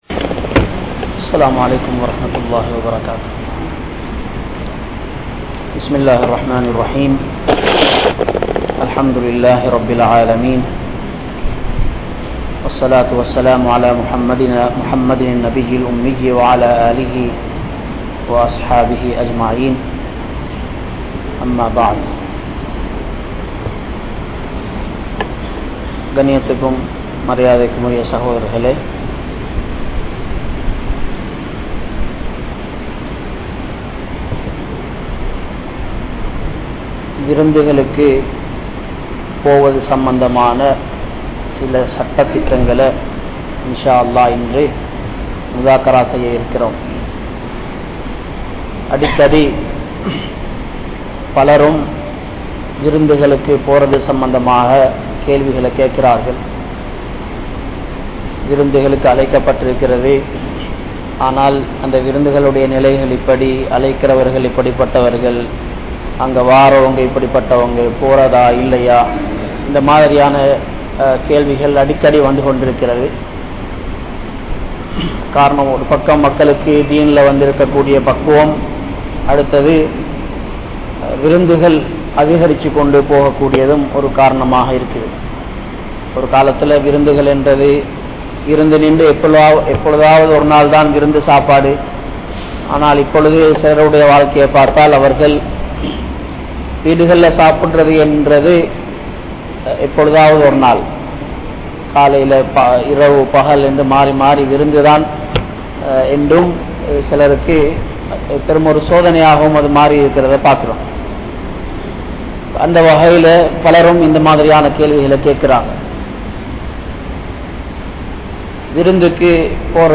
Guests & Hosts | Audio Bayans | All Ceylon Muslim Youth Community | Addalaichenai